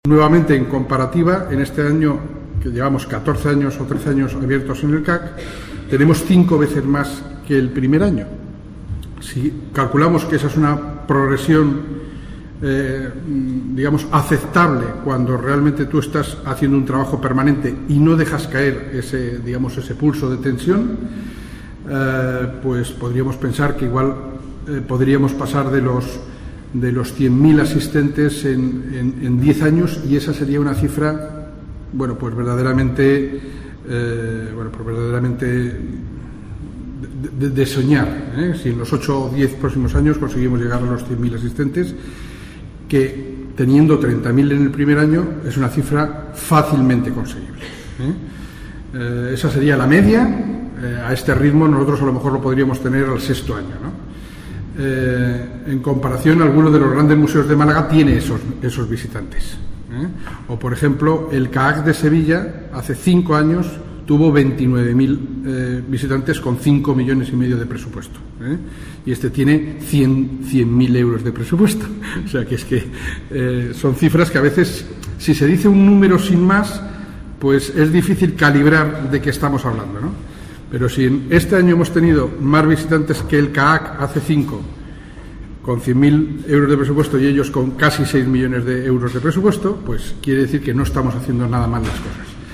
Esta rueda de prensa se enmarca dentro de la segunda jornada de la Semana de Antequera en Málaga (SAM 2016).
Cortes de voz